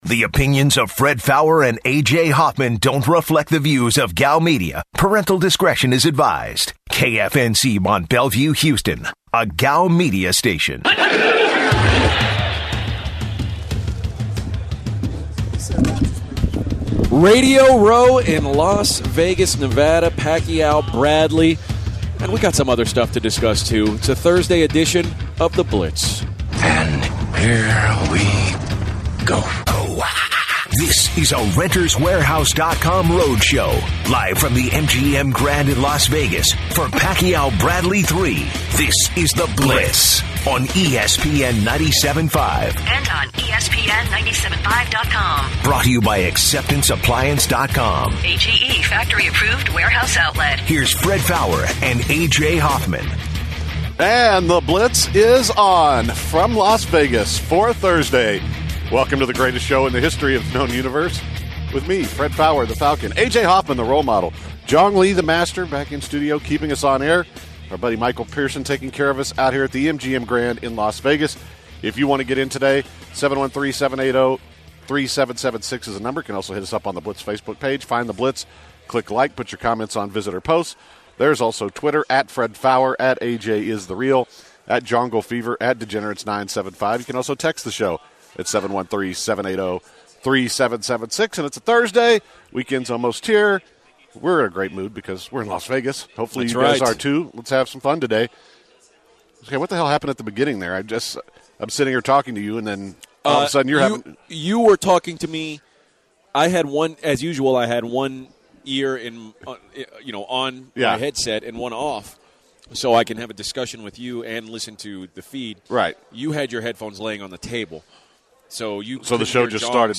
live from Las Vegas, NV, the site of the Pacquiao vs. Bradley III.